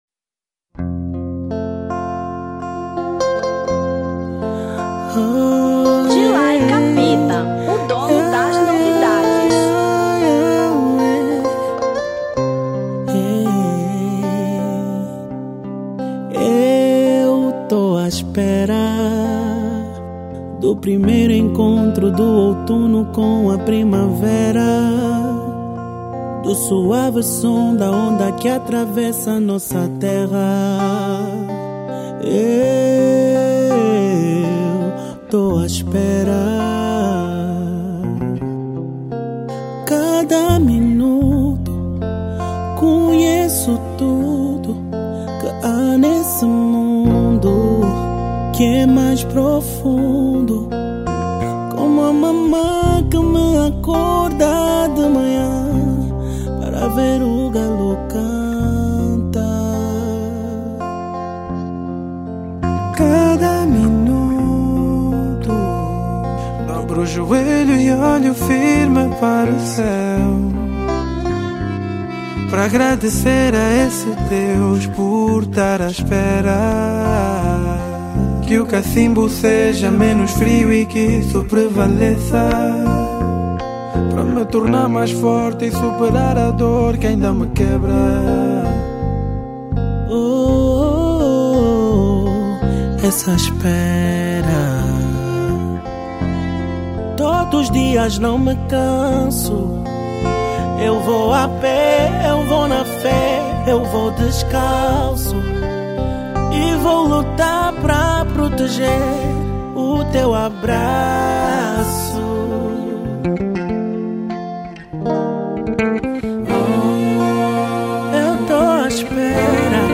Soul 2025